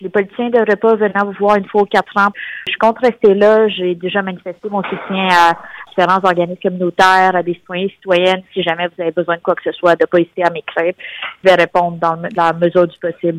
C’est ce qu’elle a déclaré en entrevue sur nos ondes ce mercredi.